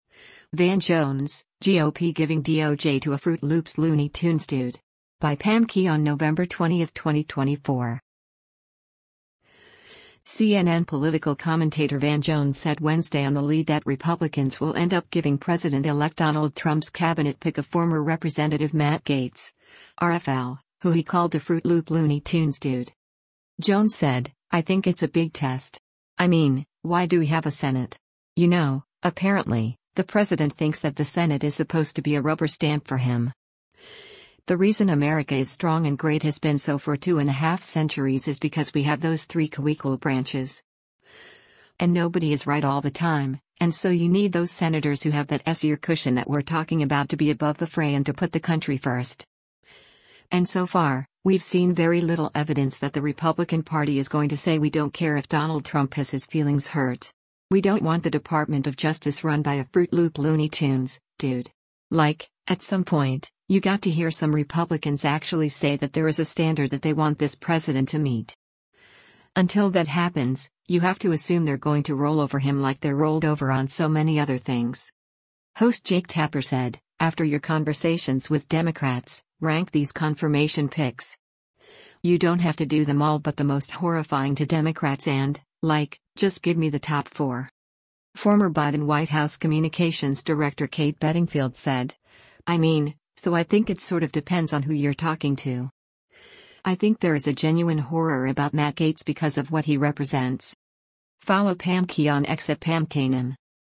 CNN political commentator Van Jones said Wednesday on “The Lead” that Republicans will end up giving President-elect Donald Trump’s cabinet pick of former Rep. Matt Gaetz (R-FL), who he called a “fruit loop looney tunes dude.”